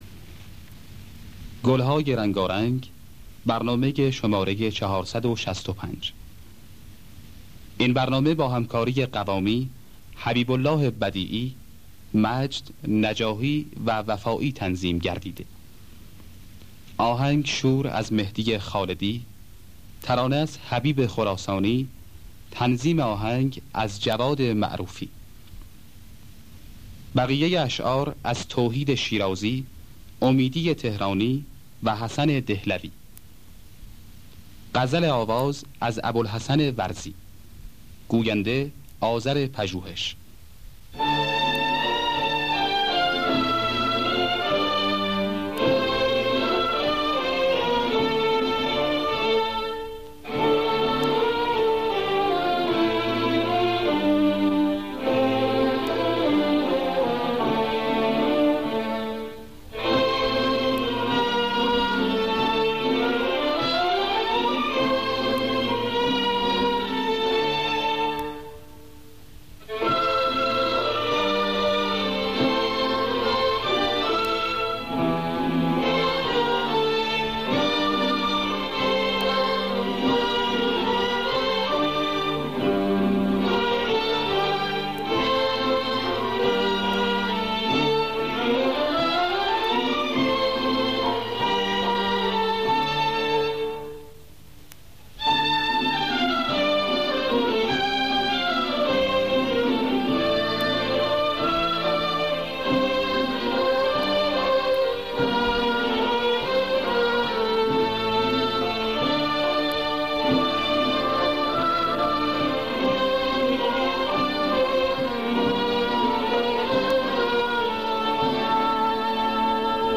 در دستگاه شور